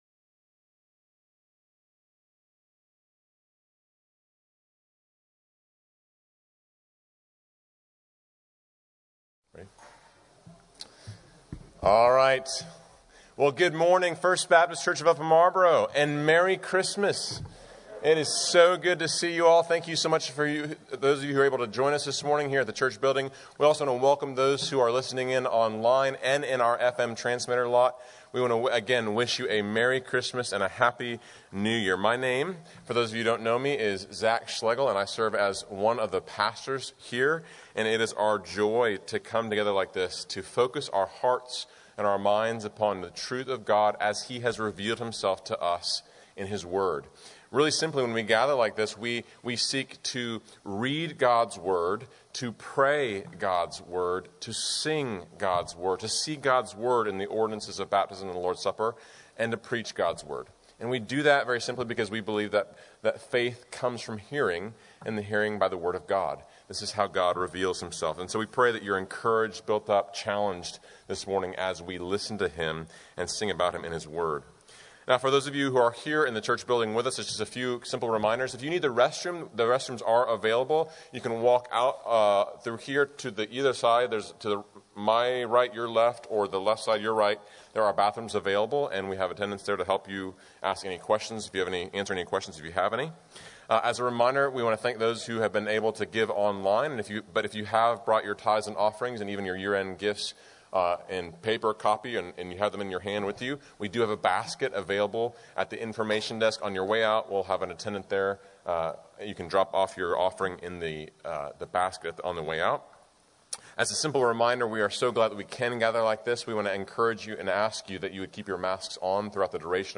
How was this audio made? SERVICE_SeekSaveLost.mp3